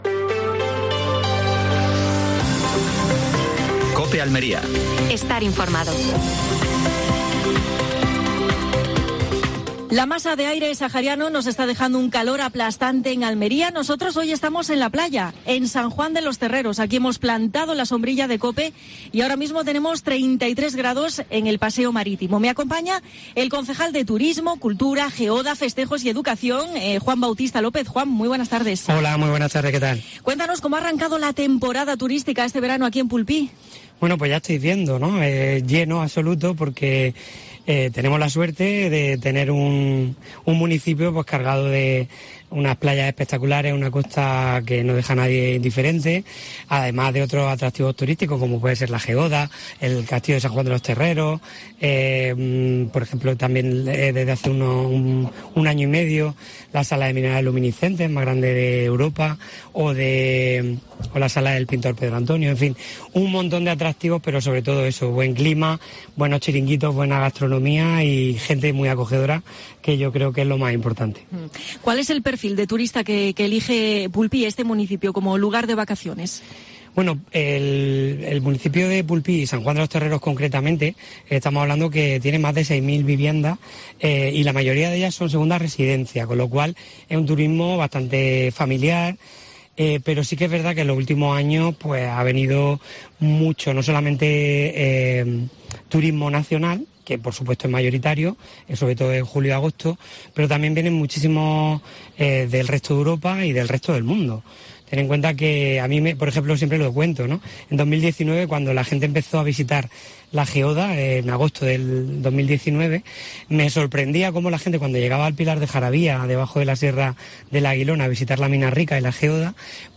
AUDIO: Última hora en Almería. Entrevista a Juan Bautista López (teniente de Alcalde de Pulpí).